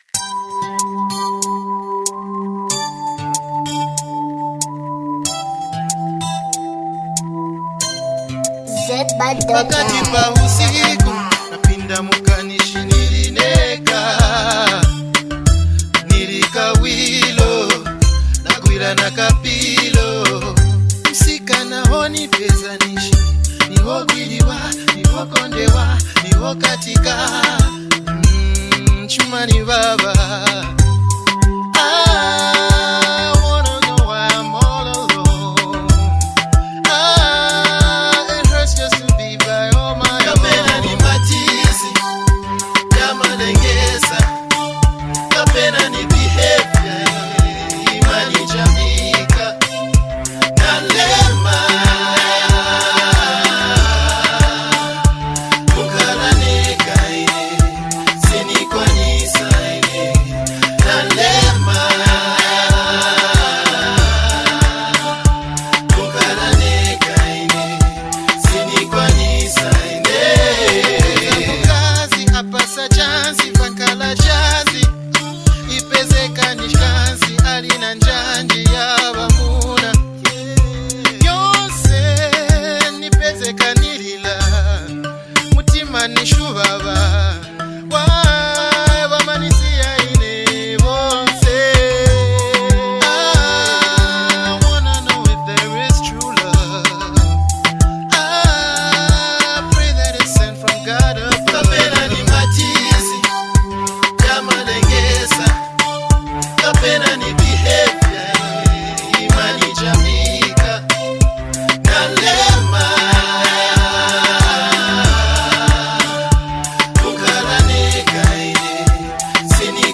The vocals are smooth and blend well with the instruments.